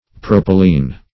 Propylene \Pro"pyl*ene\, n. [Cf. F. propyl[`e]ne.] (Chem.)